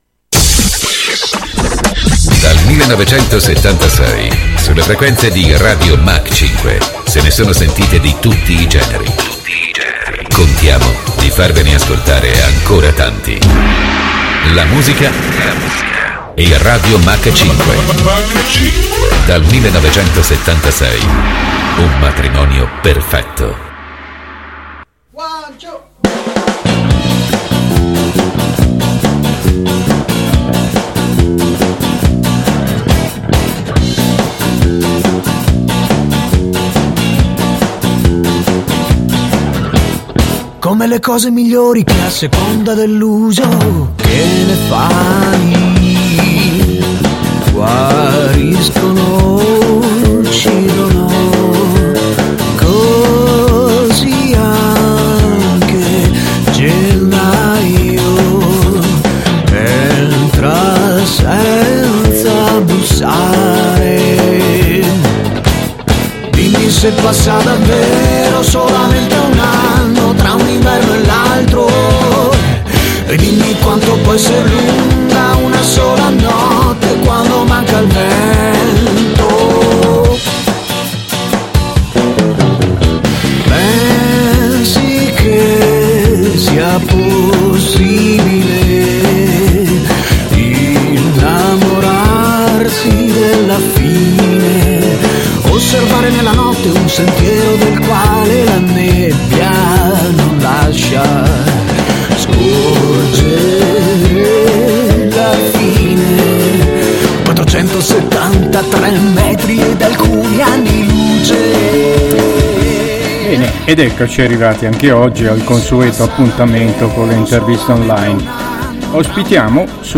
Interviste in scheda - I